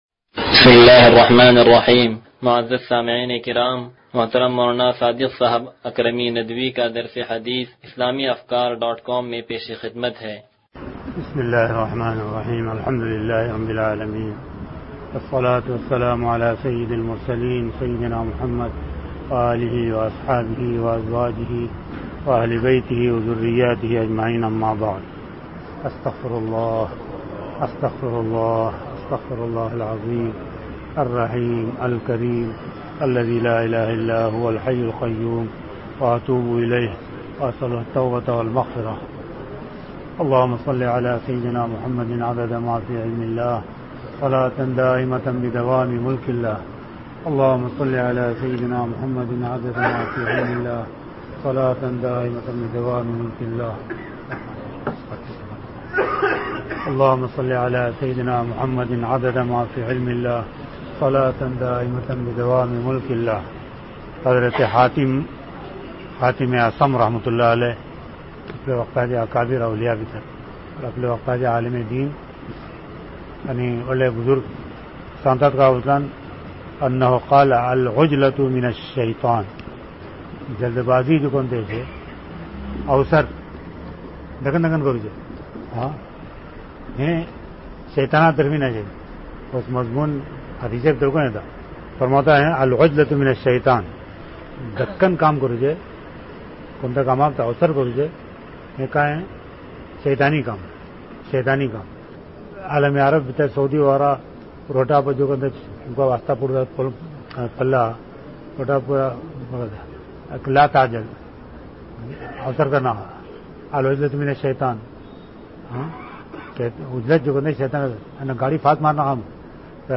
درس حدیث نمبر 0130